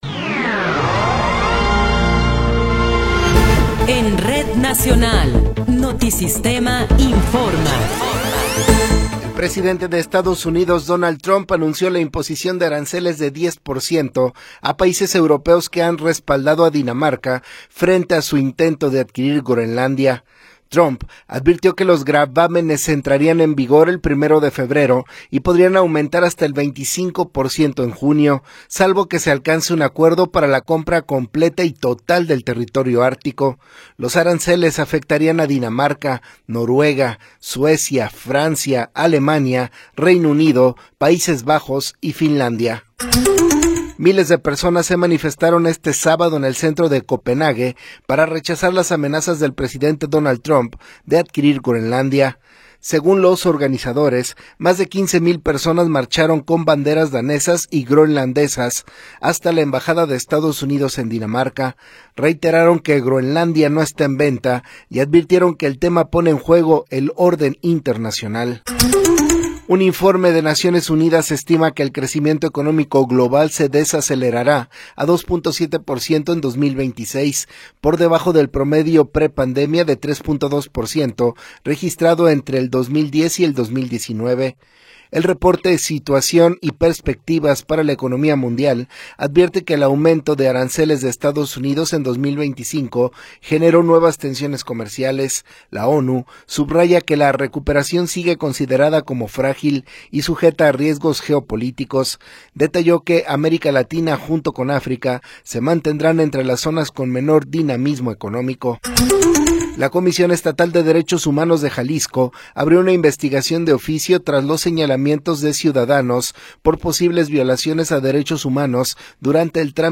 Noticiero 12 hrs. – 17 de Enero de 2026
Resumen informativo Notisistema, la mejor y más completa información cada hora en la hora.